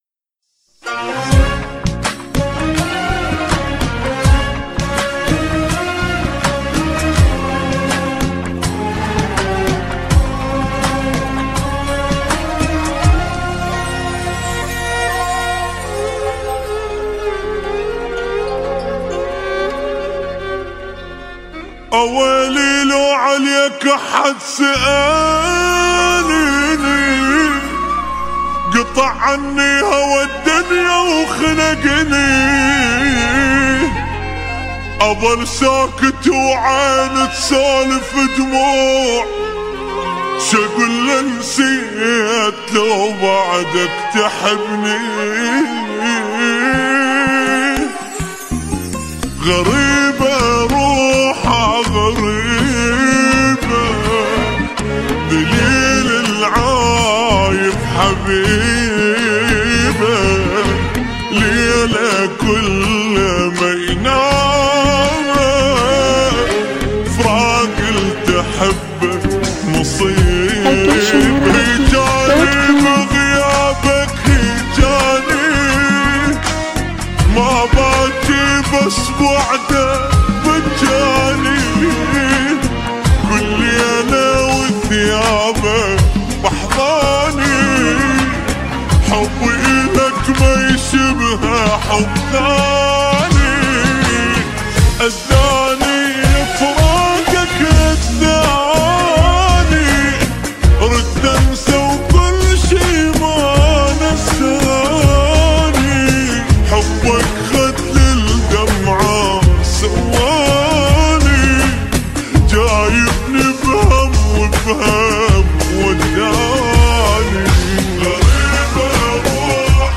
اغاني عراقيه